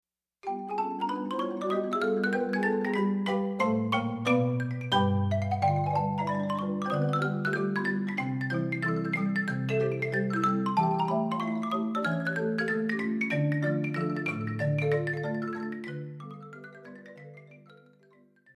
Solo, Arrangement/Transcription
Xylophone